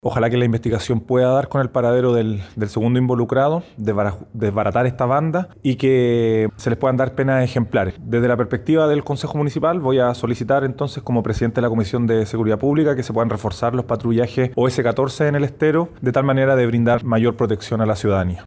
Por su parte, el concejal Alejandro Aguilera, quien también es presidente de la Comisión de Seguridad de Viña del Mar, comentó que solicitará que se refuercen los trabajos policiales preventivos en el sector.